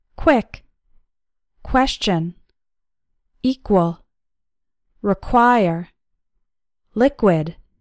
In most words, “QU” is pronounced like “KW”: